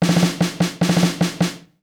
British SKA REGGAE FILL - 11.wav